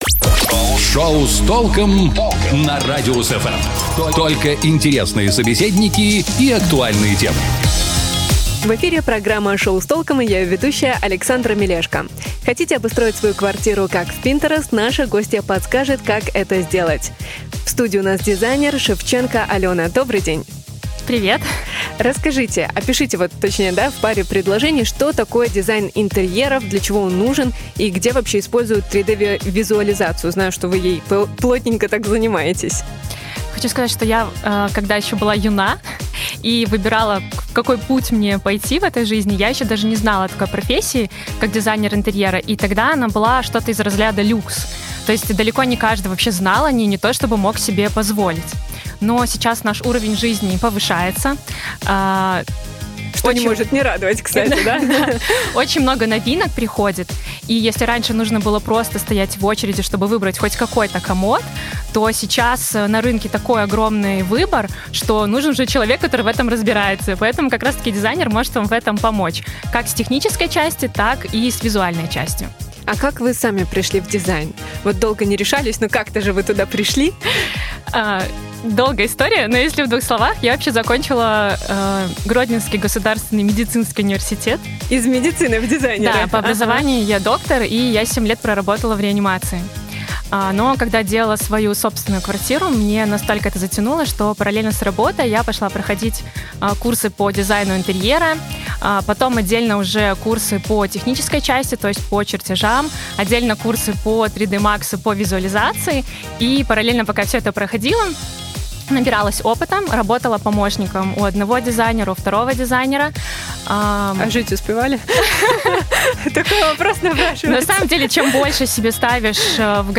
Наша гостья подскажет, как это сделать.